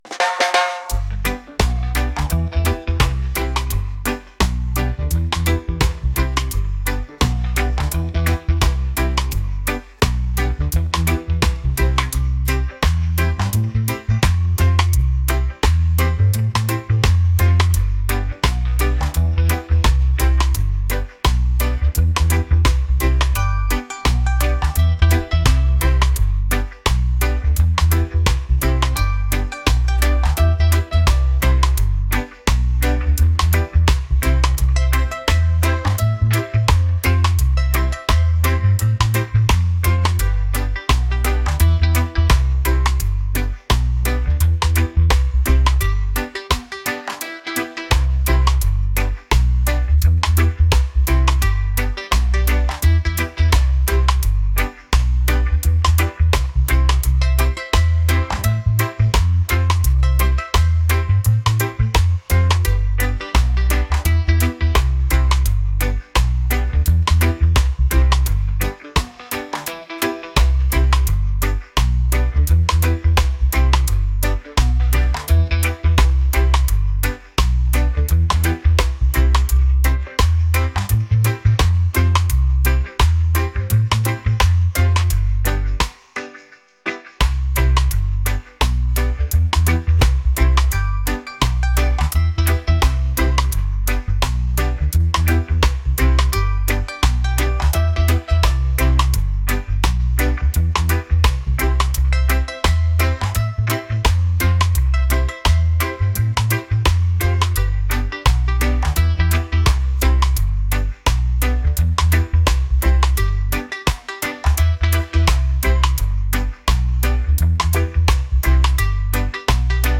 reggae | laid-back | positive